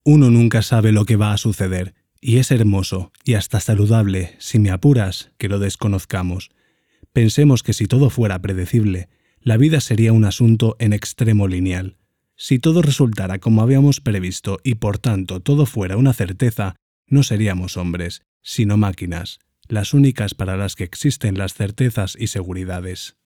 Tenor máxima profesionalidad
kastilisch
Sprechprobe: Industrie (Muttersprache):